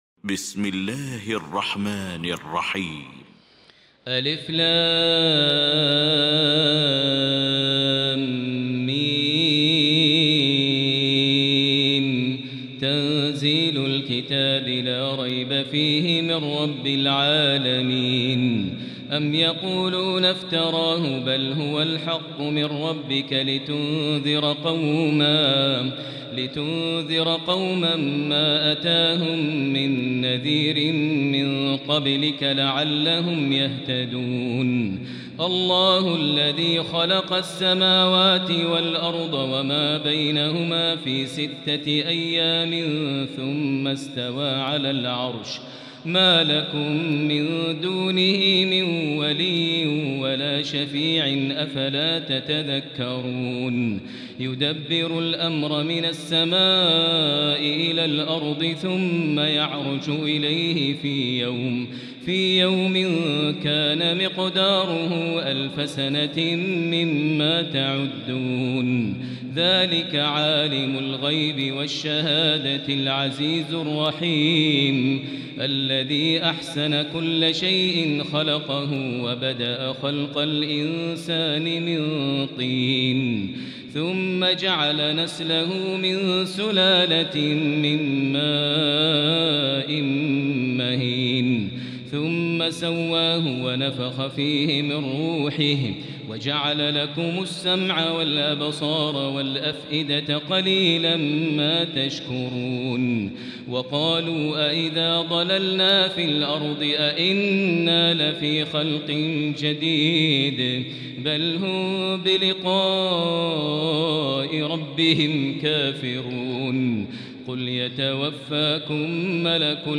المكان: المسجد الحرام الشيخ: فضيلة الشيخ ماهر المعيقلي فضيلة الشيخ ماهر المعيقلي السجدة